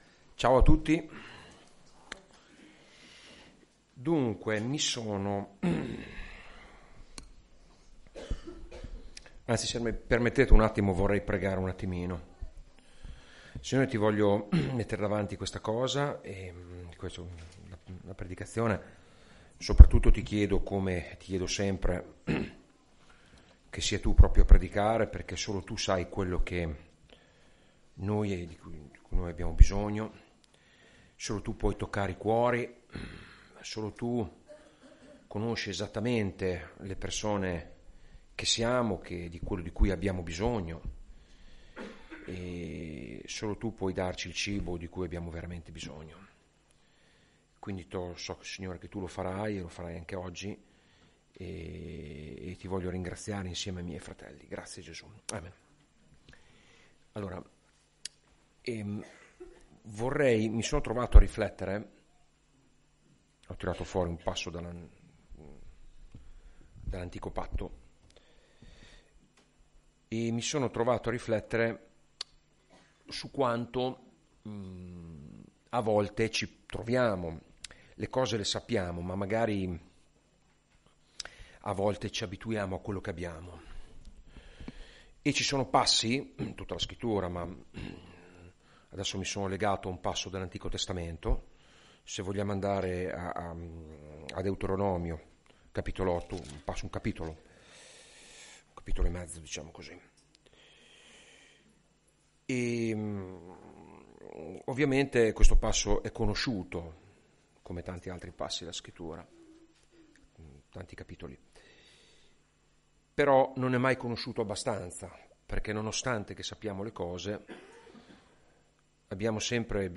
Predicazioni